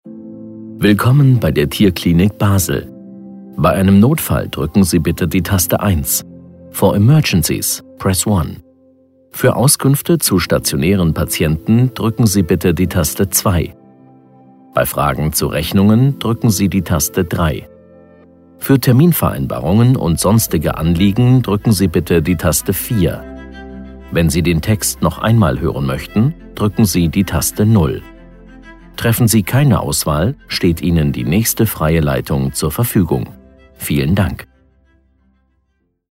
IVR Ansage – Tierklinik Basel